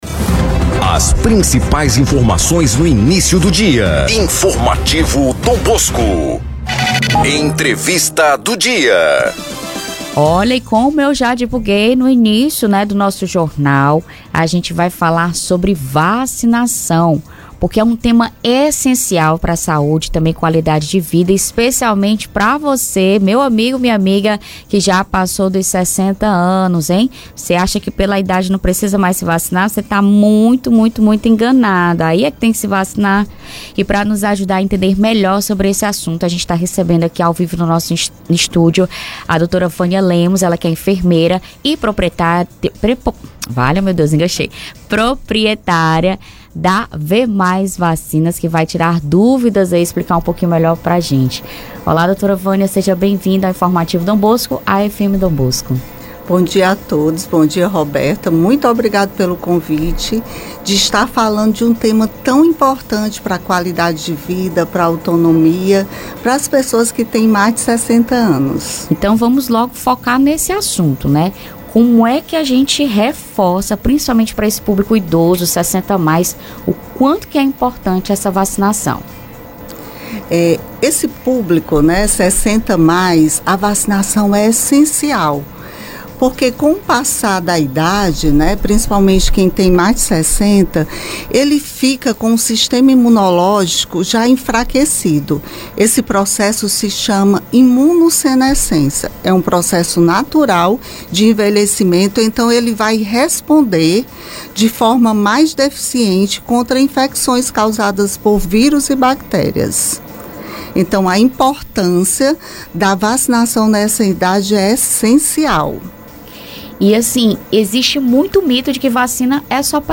ENTREVISTA_VMAIS-0209.mp3